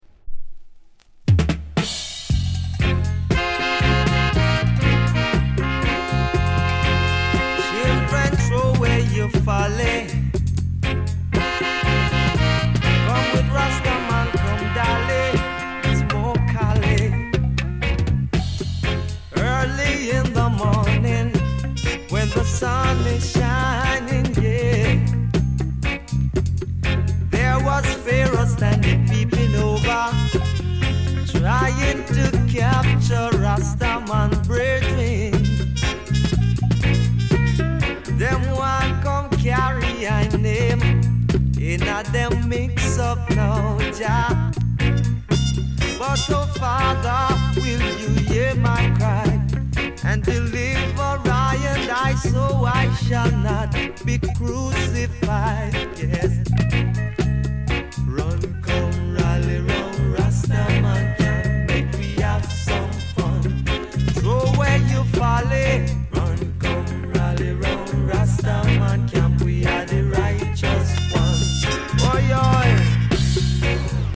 US 高音質